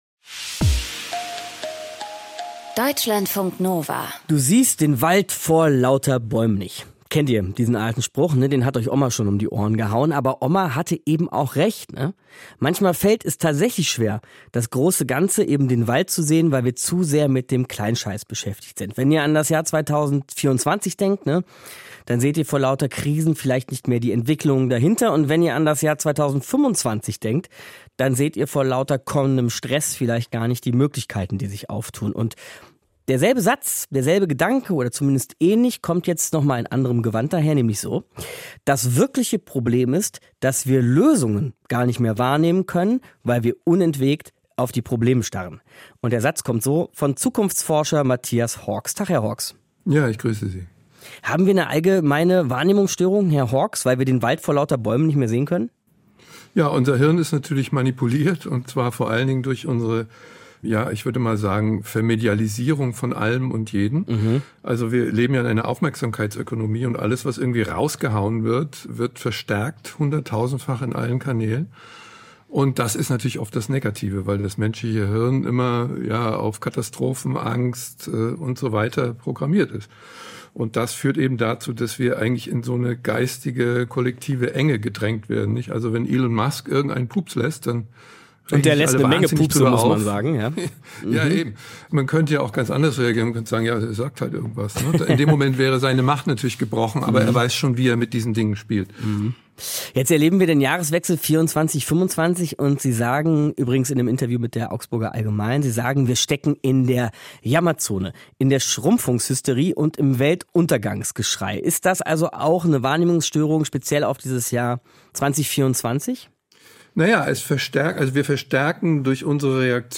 Kommentar - Leben in der kollektiven Verdrängung